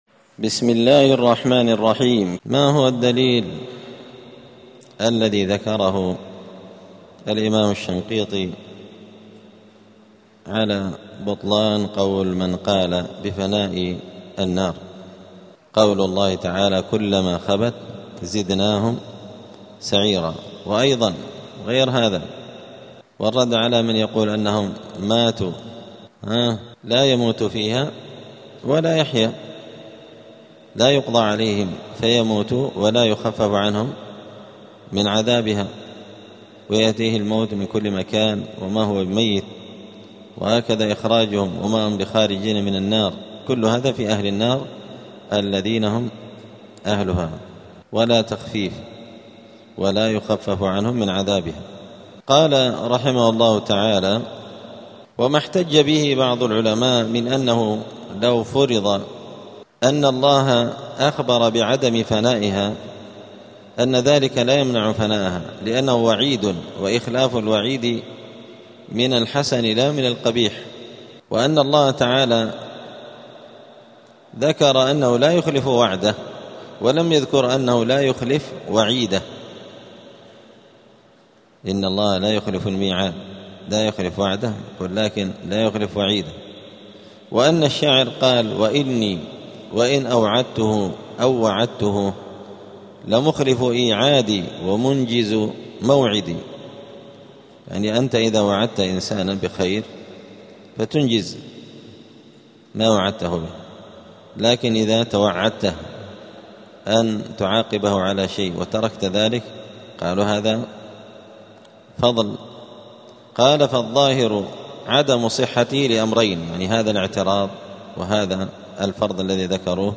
*الدرس الأربعون (40) {سورة الأنعام}.*